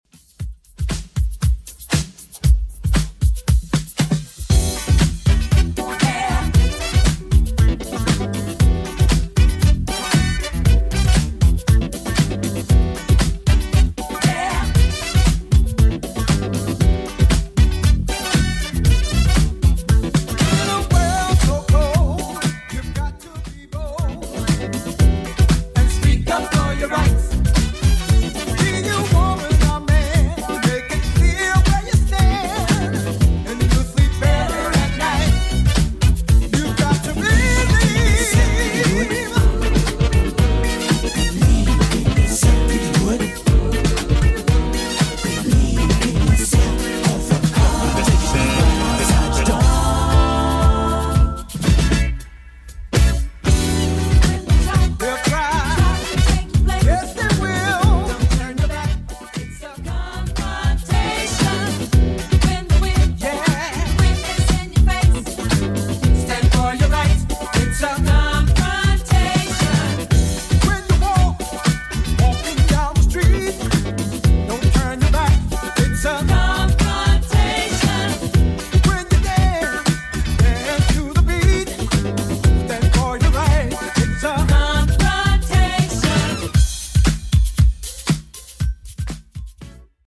Genres: 2000's , RE-DRUM , TOP40 Version: Clean